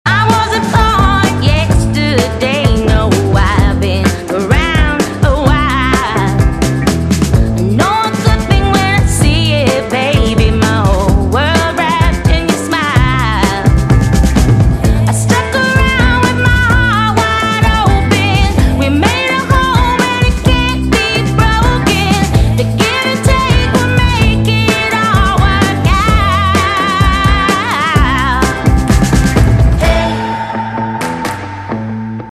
M4R铃声, MP3铃声, 欧美歌曲 23 首发日期：2018-05-14 05:43 星期一